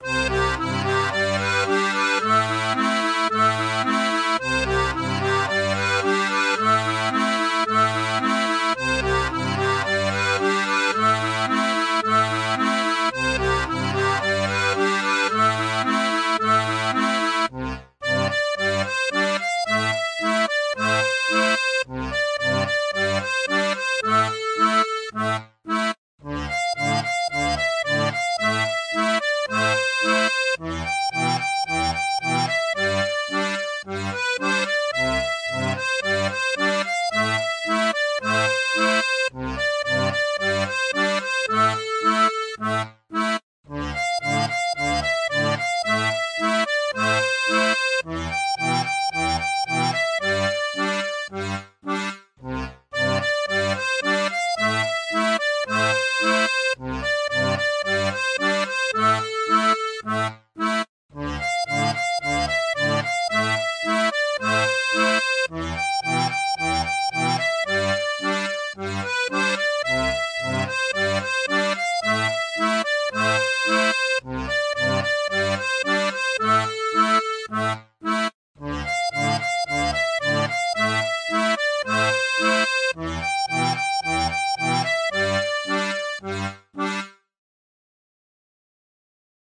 Tablature pour accordéon diatonique
Chanson française